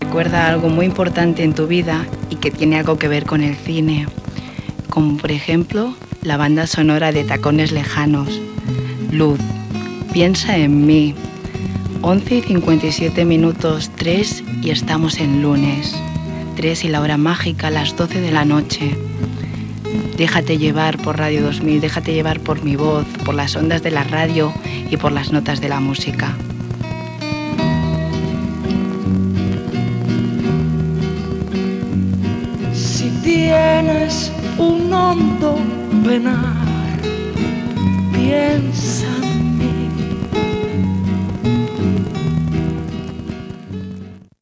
b6e71a4d5c8b0b64f04ef22235ecc8df763405a7.mp3 Títol Ràdio 2000 Emissora Ràdio 2000 Titularitat Tercer sector Tercer sector Comercial Descripció Presentació d'un tema musical amb identificació.